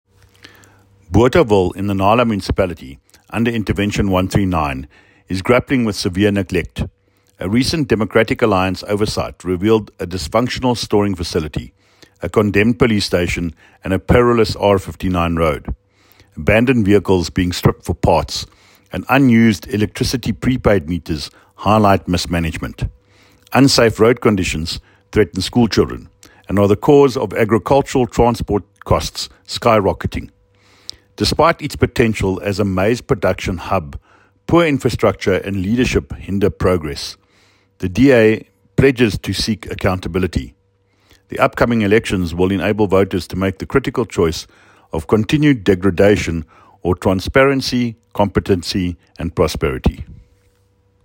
Afrikaans soundbites by David Mc Kay MPL and